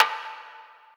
Perc - Metro Reverb.wav